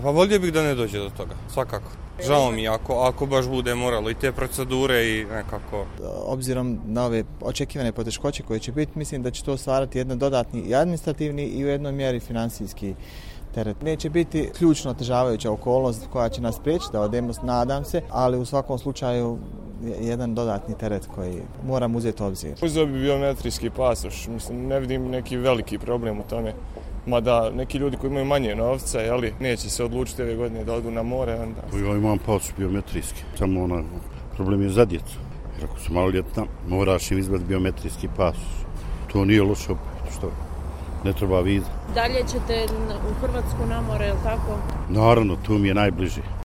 Prema nekim procjenama takvih je oko 115.000. Ipak, građani Sarajeva u tome ne vide veliki problem: